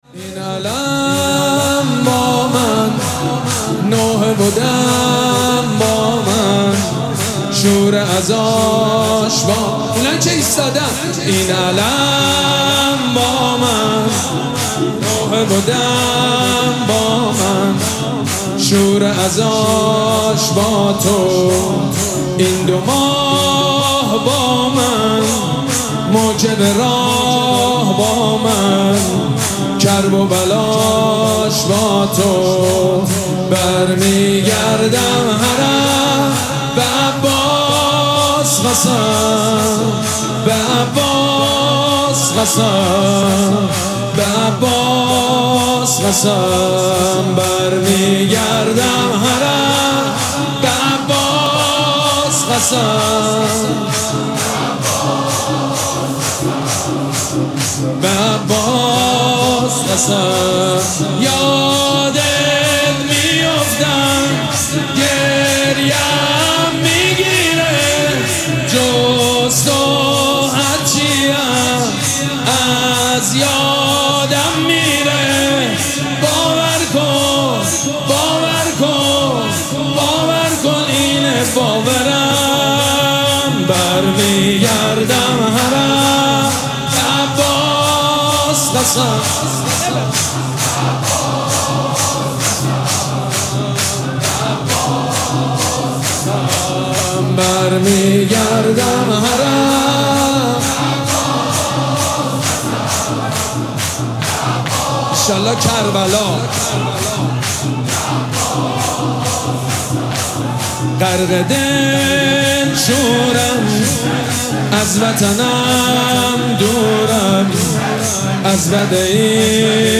مراسم عزاداری شام غریبان محرم الحرام ۱۴۴۷
شور
مداح
حاج سید مجید بنی فاطمه